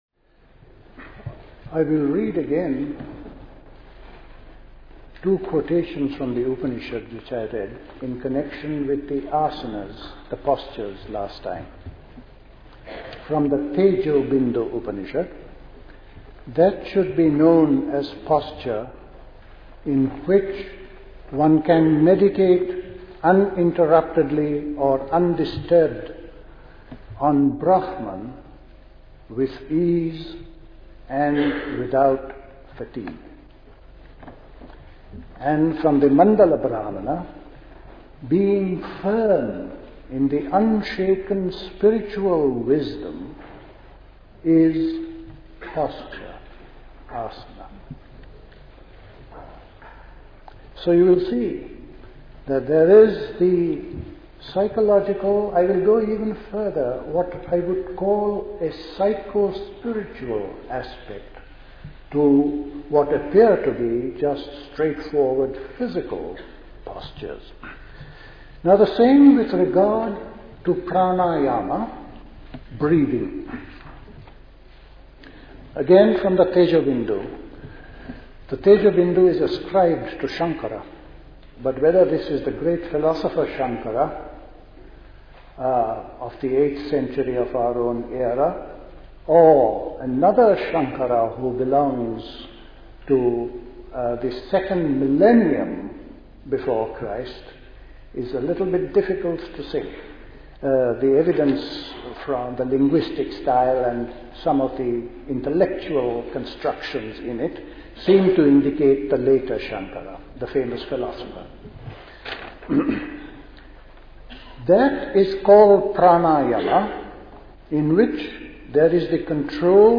A talk
at Caxton Hall, Westminster, London